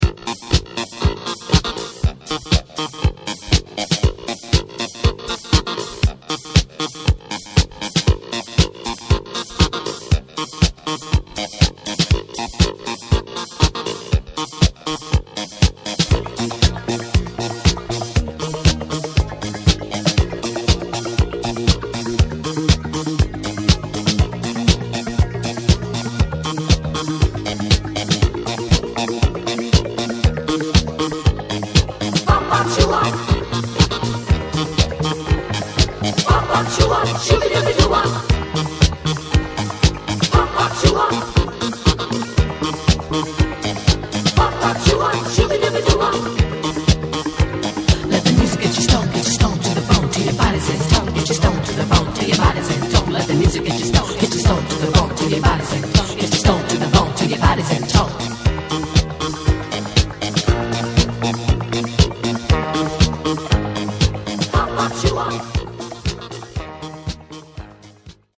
DISCO RINGTONES